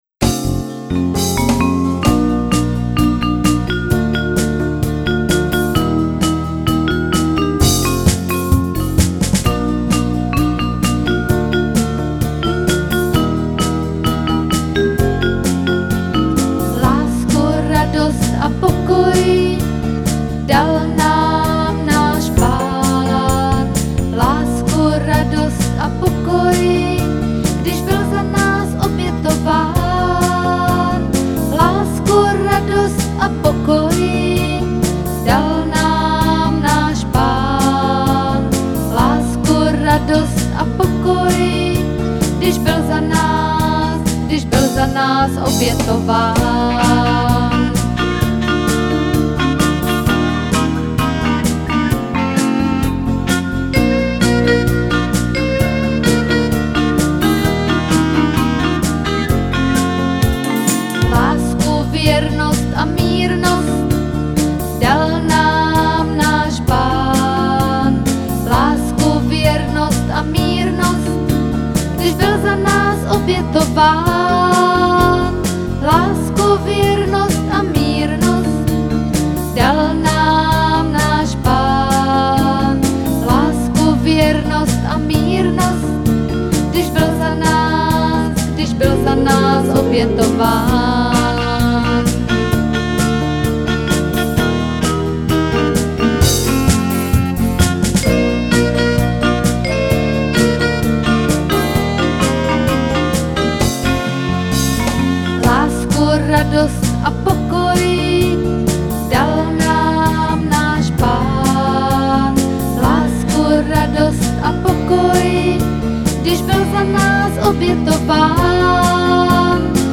Křesťanské písně
Písničky pro děti